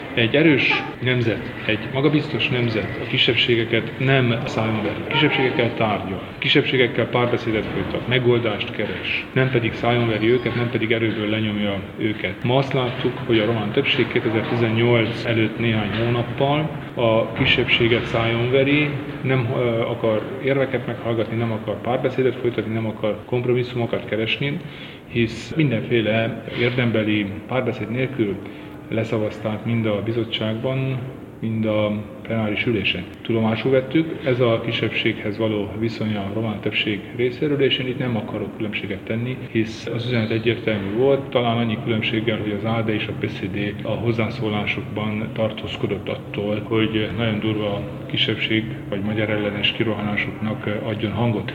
Kelemen Hunor szövetségi elnök nyilatkozata itt meghallgatható:
kelemen-hunor-szovetsegi-elnok-nyilatkozata.mp3